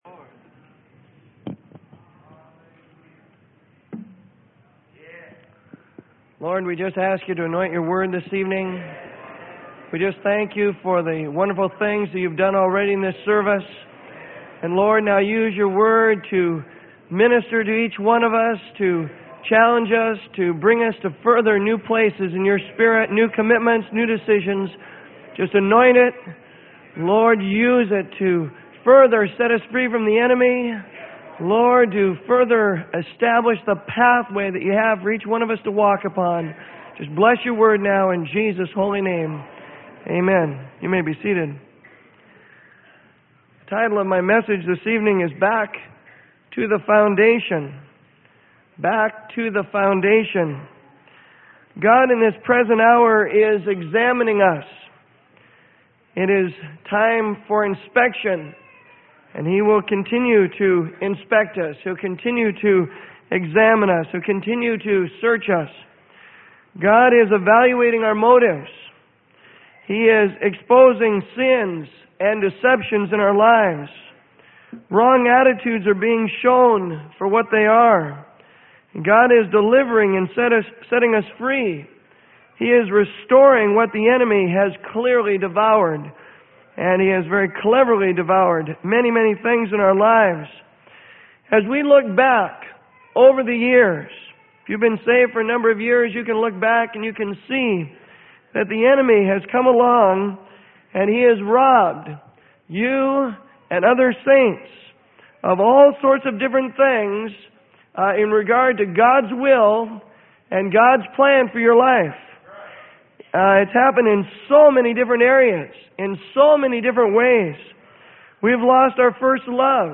Sermon: Back To The Foundation.